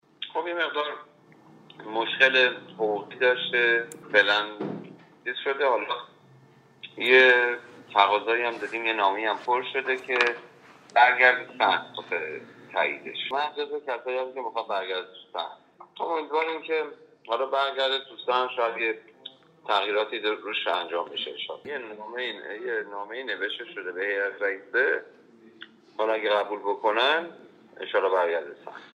یک نماینده مجلس در گفتگو با بهمن: